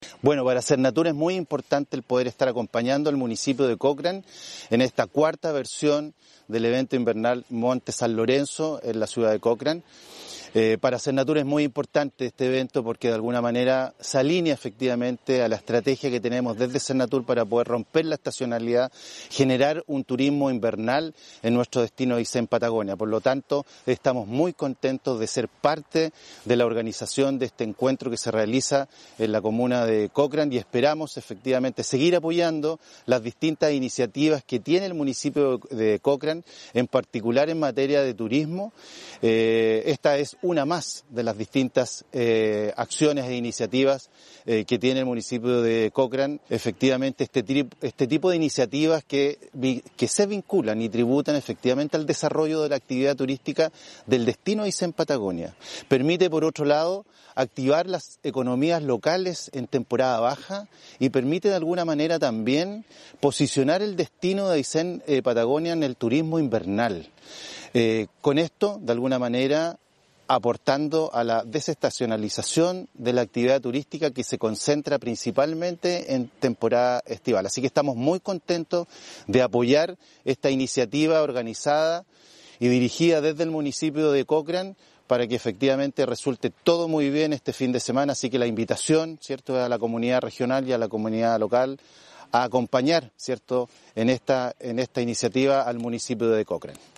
CLAUDIO MONTECINOS – DIRECTOR SERNATUR